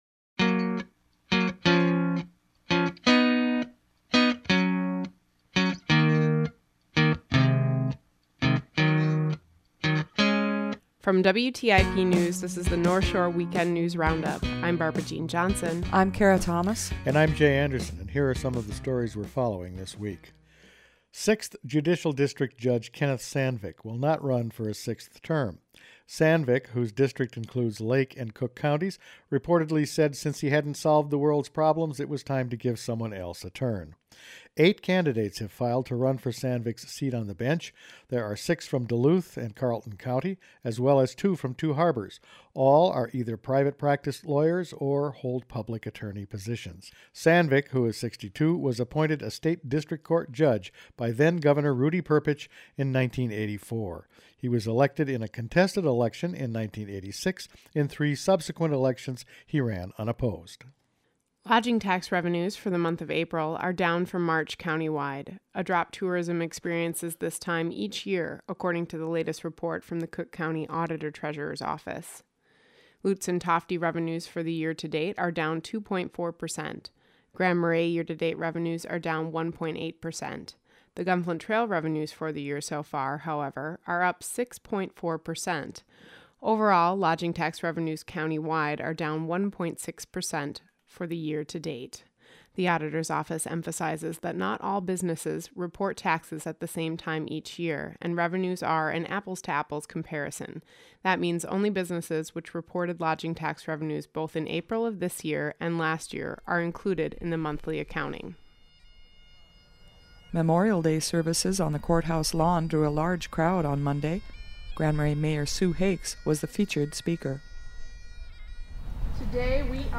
Each week the WTIP News Department provides a summary of the stories it has been following that week.